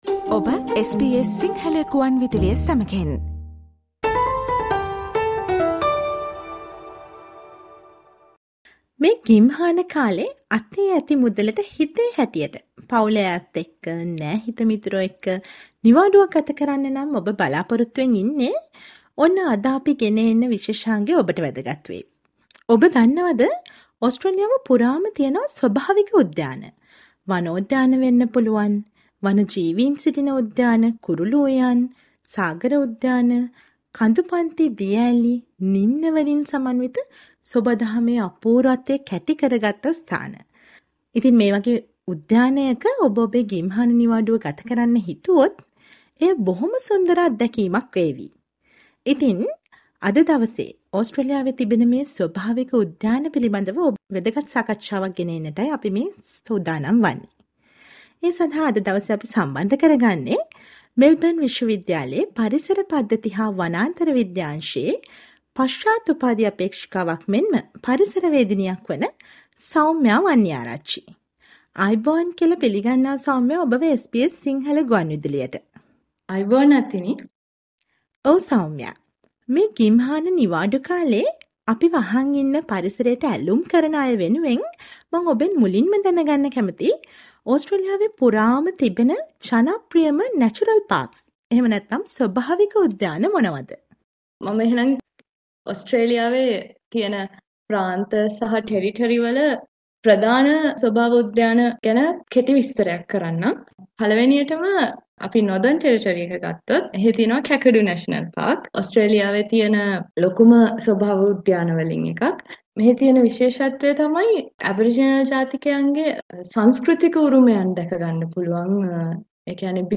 Listen to the SBS Sinhala radio interview on the beauty of the national park across Australia and the important things to concern when you are planning this summer holidays to visit a natural park with your family.